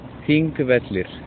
Þingvellir (Icelandic: [ˈθiŋkˌvɛtlɪr̥]
Is-Þingvellir.oga.mp3